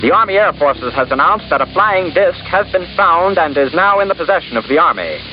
roswell-news-report.mp3